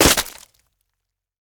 Flesh Drop Sound
horror